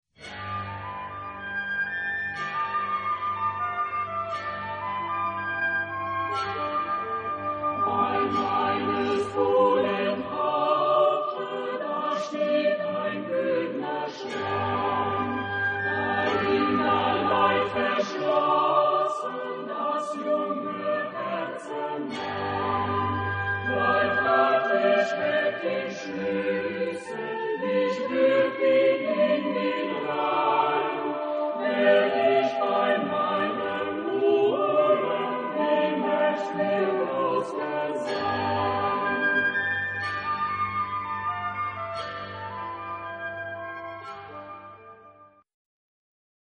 Epoque: 16th century
Genre-Style-Form: Partsong ; Folk music ; Secular
Type of Choir: SAATBB  (6 mixed voices )
Tonality: G major